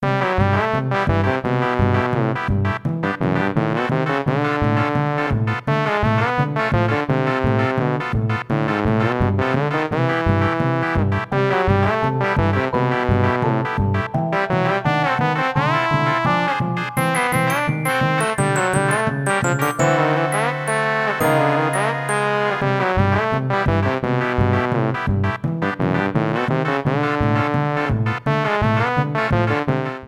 Results music
Trimmed file to 30 seconds, applied fadeout